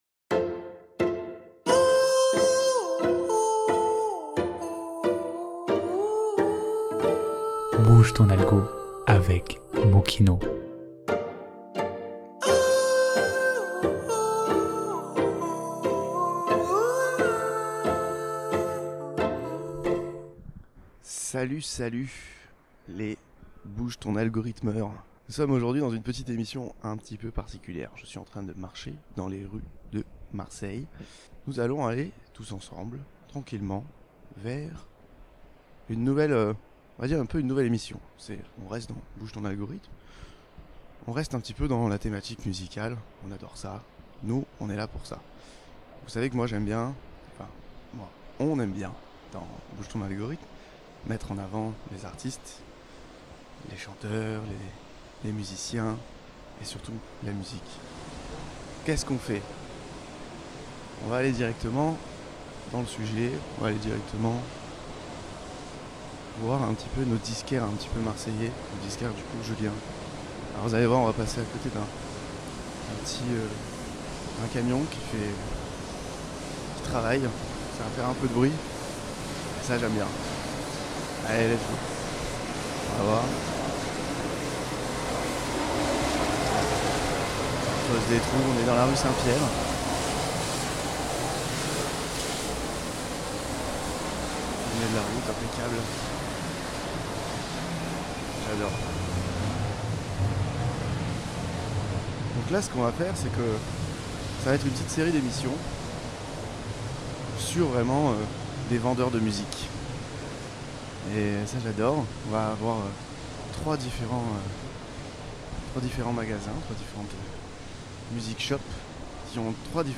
Bouge ton Algo - Galette Records Vendredi 14 Novembre 2025 Émission spéciale en immersion chez nos disquaires marseillais pour découvrir l’envers du décor musical local.